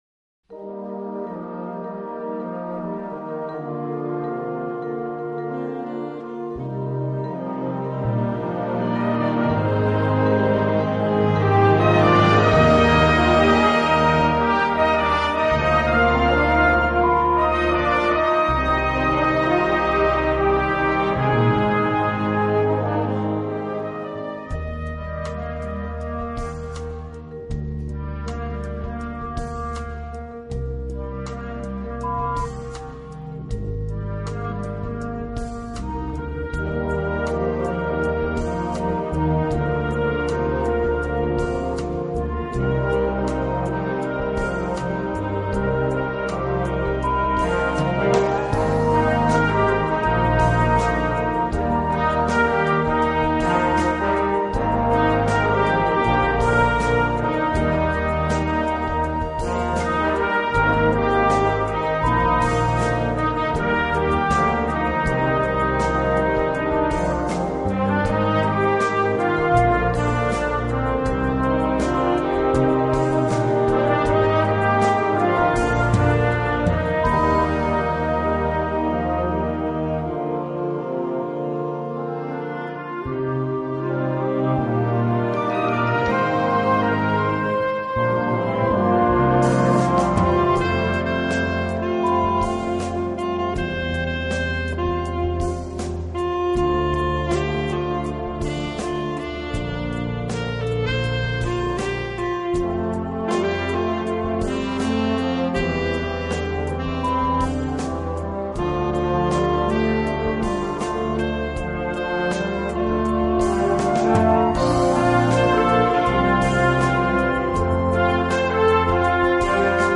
Gattung: Pop-Hit
Besetzung: Blasorchester